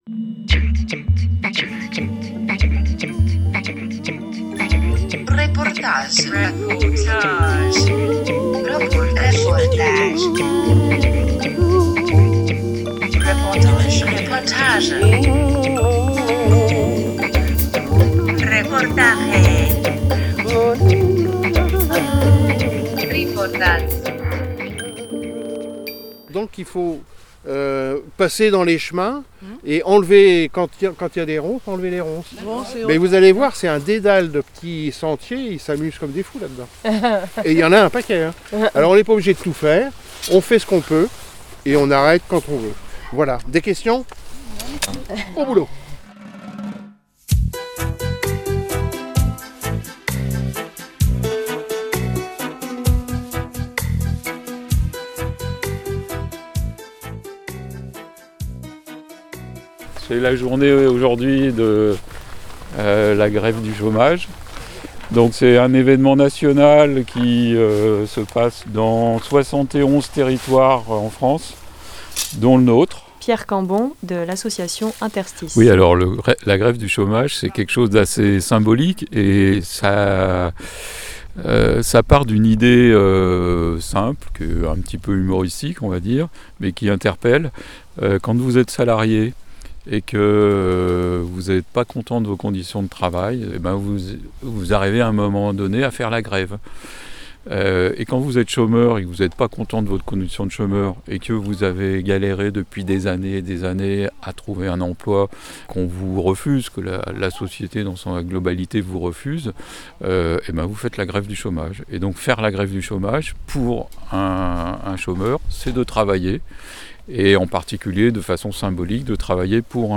15 novembre 2021 16:28 | reportage
En cette matinée du 9 novembre, les grévistes (personnes éloignées de l’emploi, élus, citoyens) s’étaient donné rendez-vous à la Ferme Saint-Pol, à la Bégude-de-Mazenc, pour un chantier de débrous